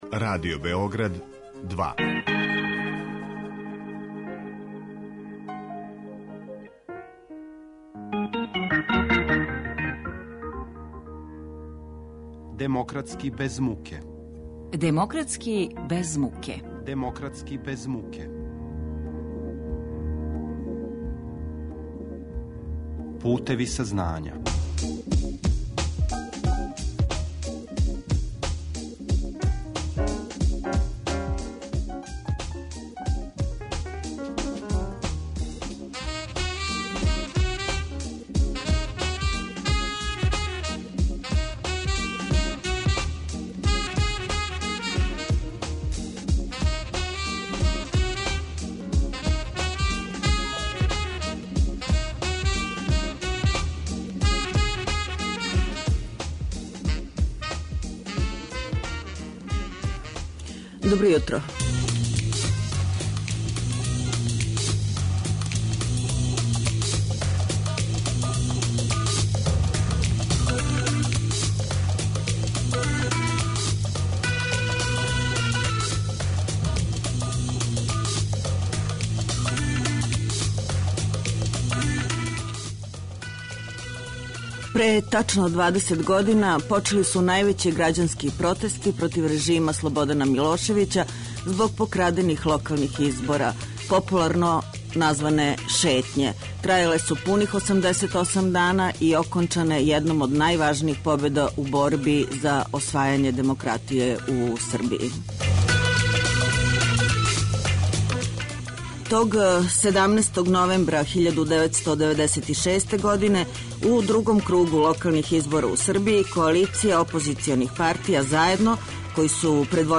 Поред подсећања, уз документарне снимке говора лидера коалиције "Заједно", о овом времену, успесима и промашајима, о неоствареним сновима, говори Весна Пешић.